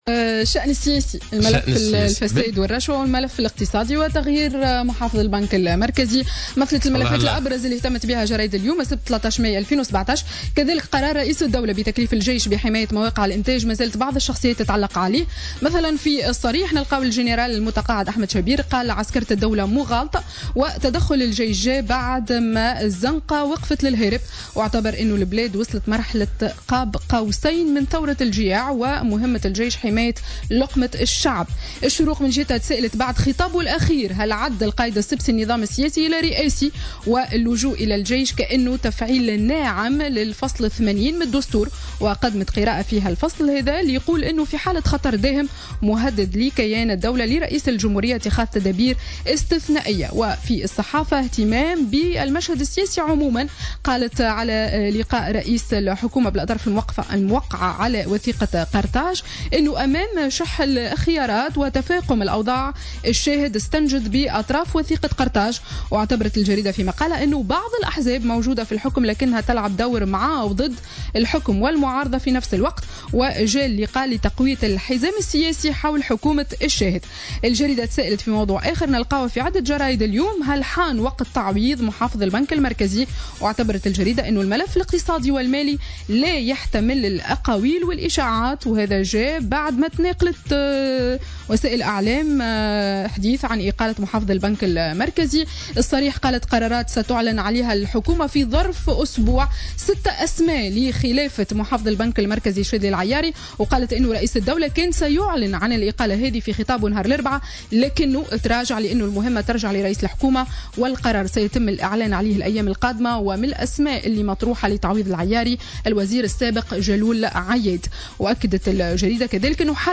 Revue de presse du samedi 13 Mai 2017